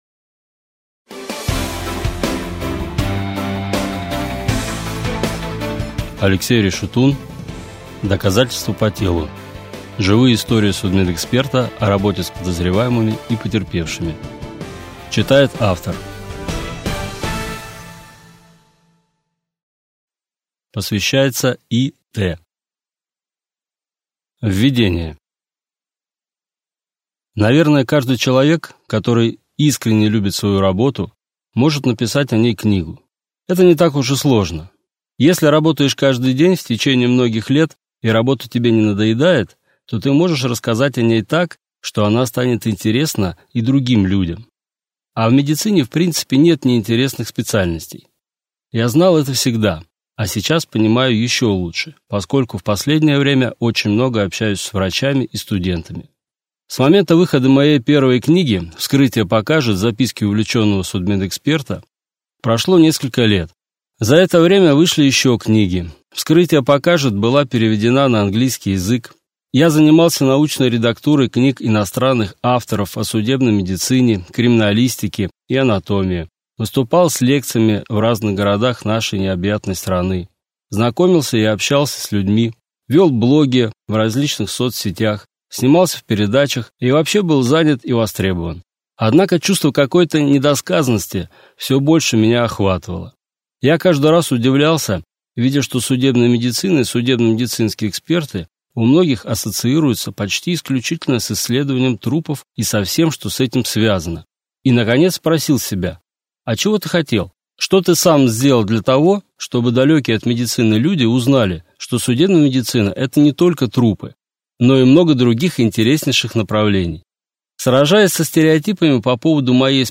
Аудиокнига Доказательство по телу: Живые истории судмедэксперта о работе с потерпевшими и подозреваемыми | Библиотека аудиокниг